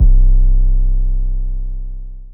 Metro 808 Dump.wav